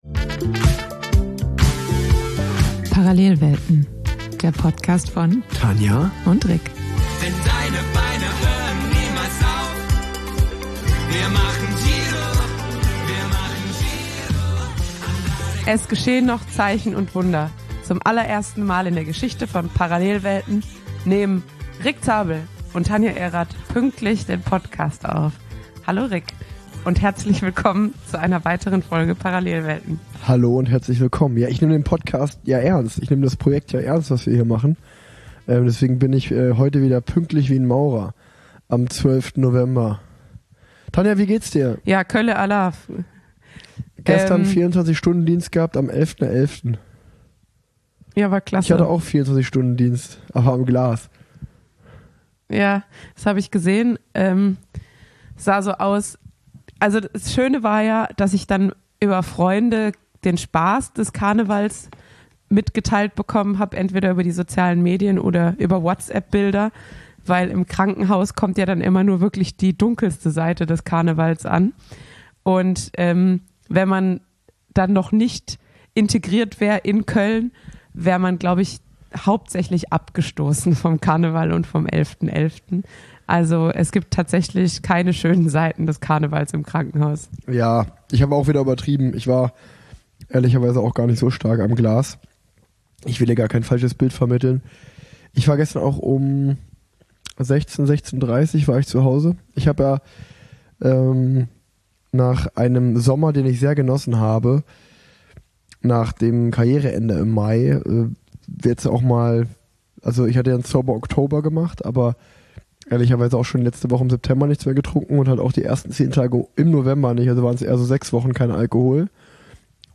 1 Interview